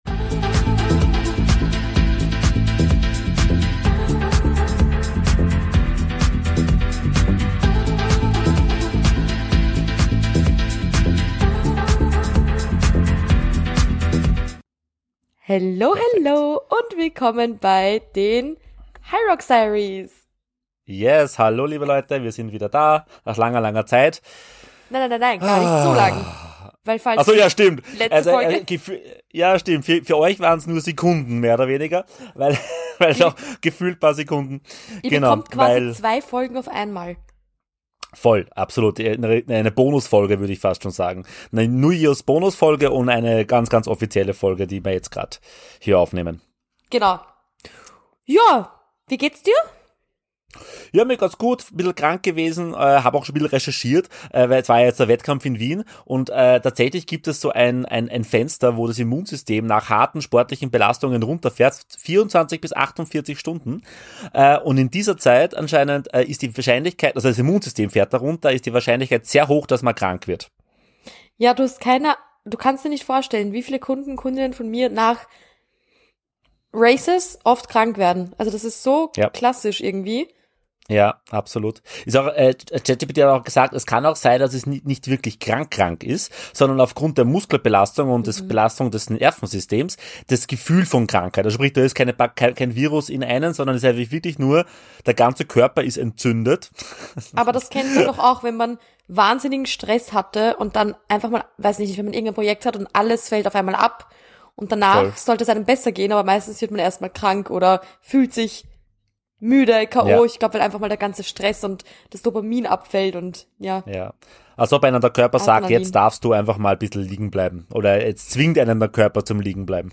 Zwischendurch wird es auch emotional und genau das darf sein.